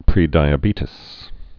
(prēdī-ə-bētĭs, -tēz)